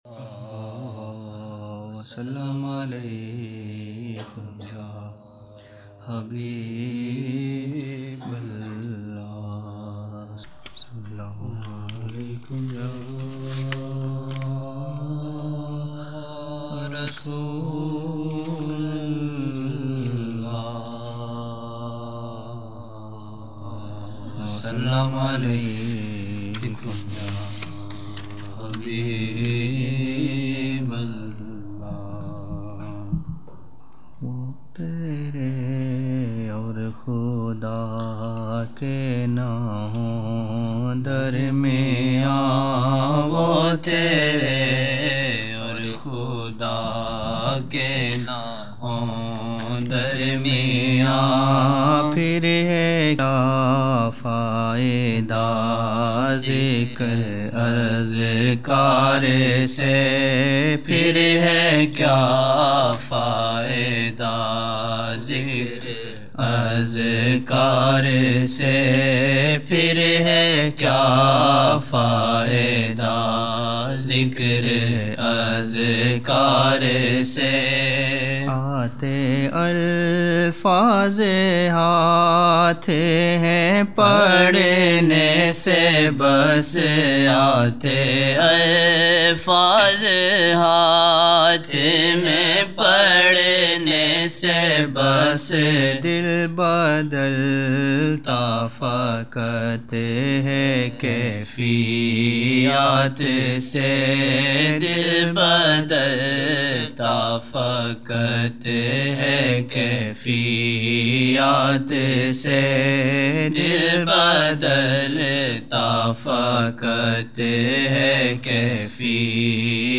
18 November 1999 - Isha mehfil (10 Shaban 1420)